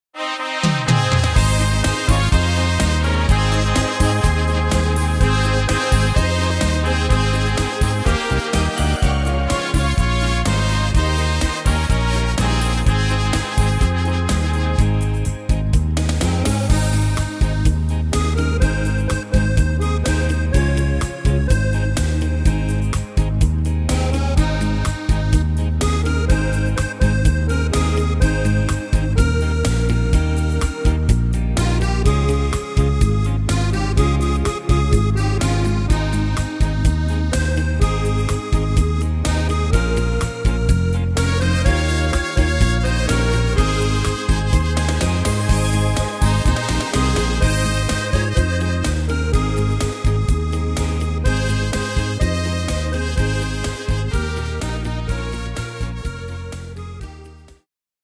Tempo: 125 / Tonart: Ab-Dur